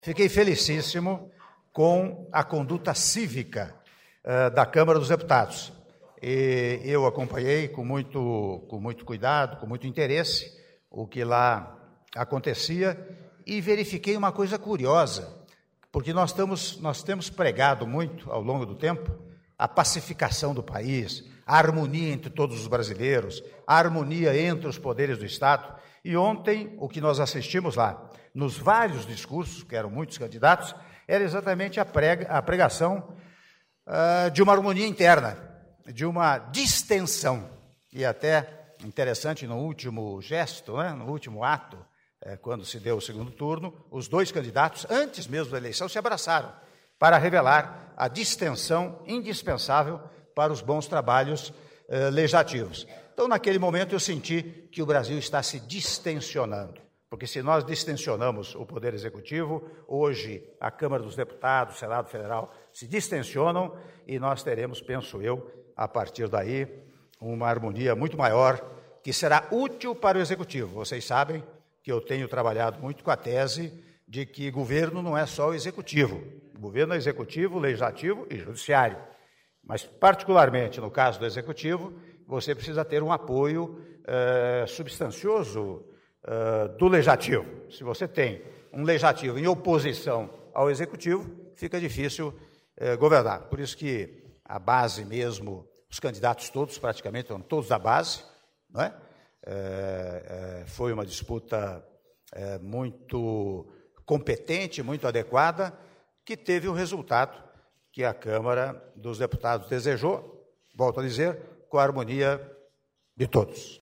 Áudio da declaração à imprensa do presidente da República em exercício, Michel Temer, após cerimônia de anúncio de nova norma do Programa Minha Casa Minha Vida (01min53s) - Brasília/DF